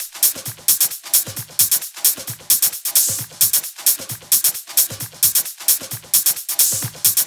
VFH3 132BPM Elemental Kit 7.wav